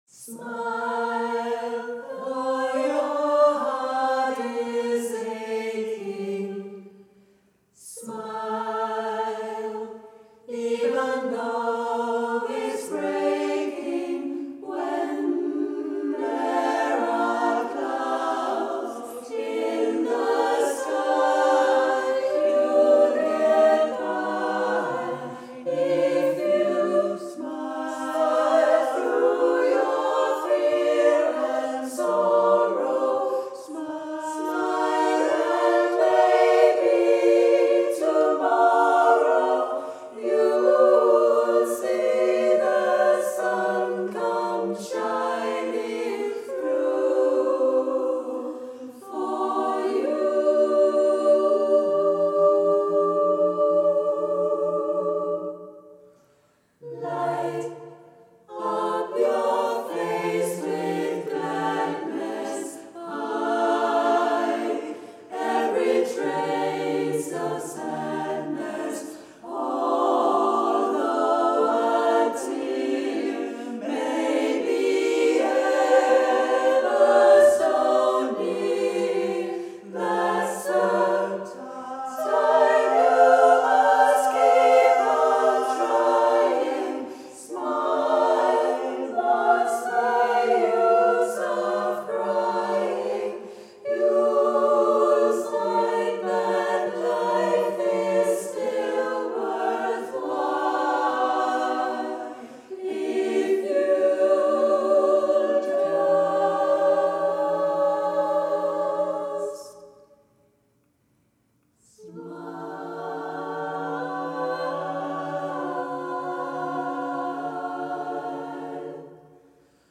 Klang ud over det sædvanlige, leg med skæve rytmer
og virtuos sang, fra den dybeste alt-klang
til den højeste  soprantone –